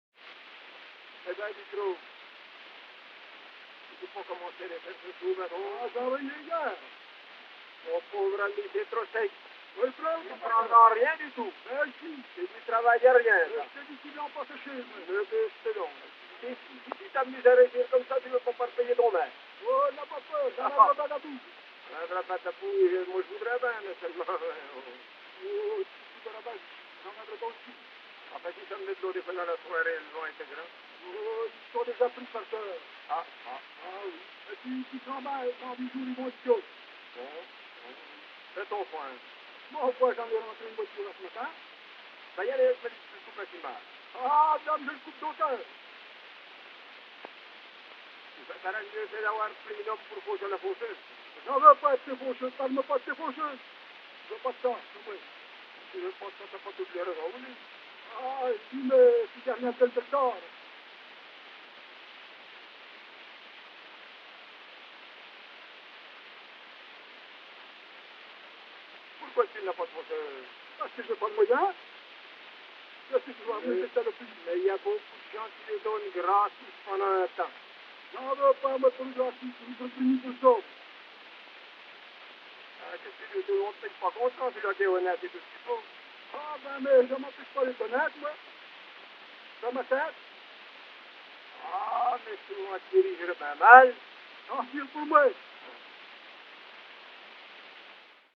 Ce sera à La Châtre le 28 juin 1913, Nohant-Vic le 29 juin et Saint-Chartier le 30 juin
Conversation dialoguée